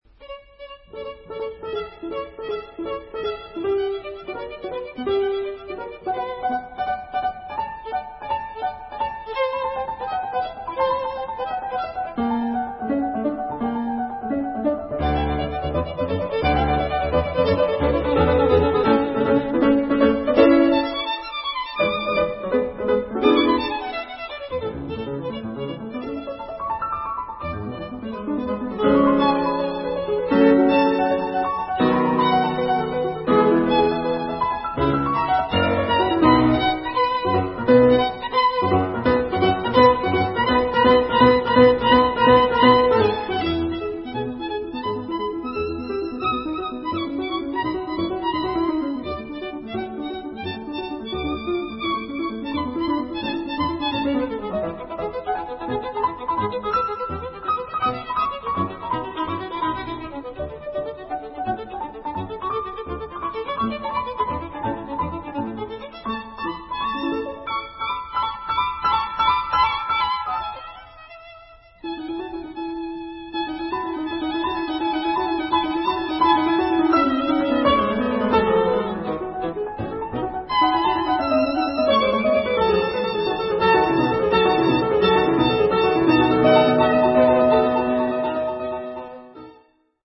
В заключительной части сонаты, finale (presto), вариации продолжаются, но как же разительно отличаются они от «пошлых варьяций» части второй!
Выход найден, и мысль повторяется, но уже уверенно, и музыка — ликует.